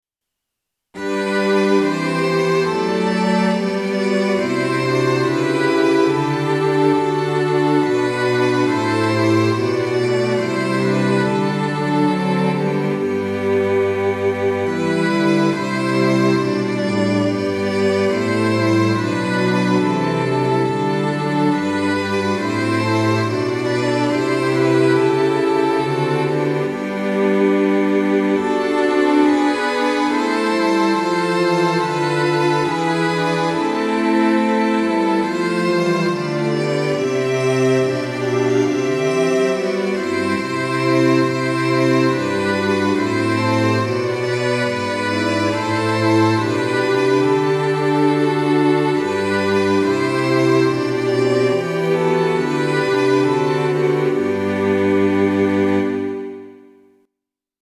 Sample Sound ：MIDI⇒MP3
Tonality：G (♯)　Tempo：Quarter note = 70